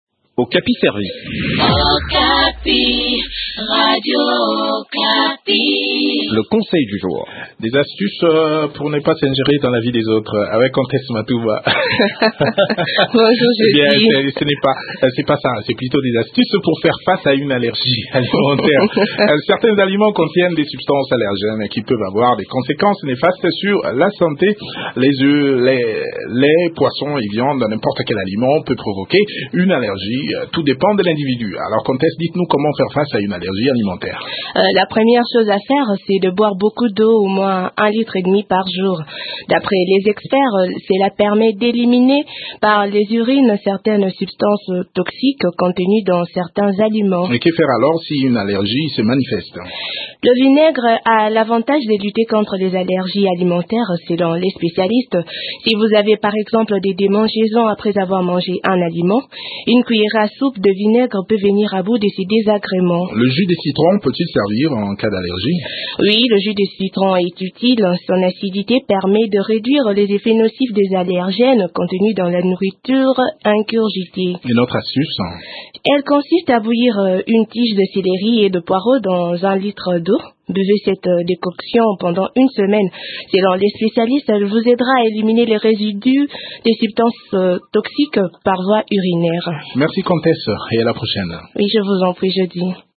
Découvrons des astuces dans cette chronique préparée par